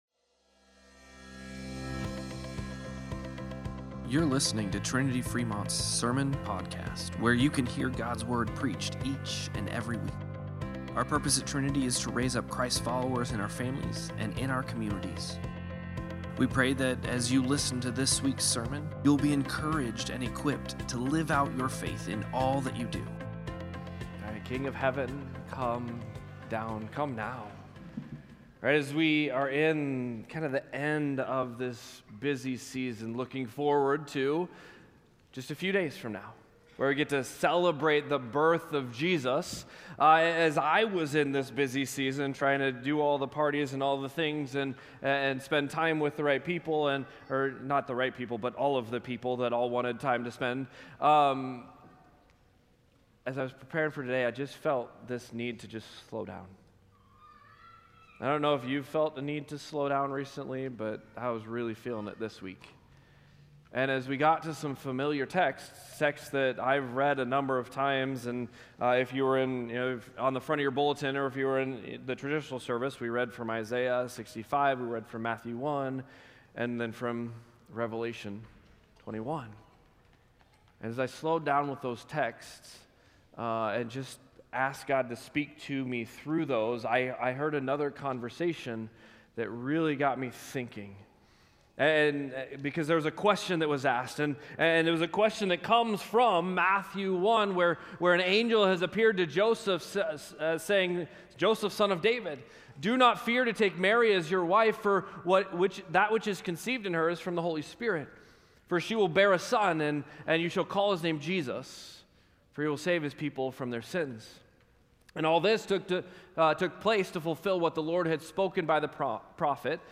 Sermon-Podcast-12-21.mp3